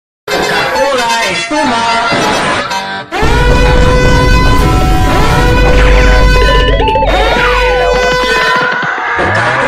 Pranks
Goofy Ahh Loud Annoying Sounds